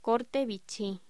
Locución: Corte vichy
voz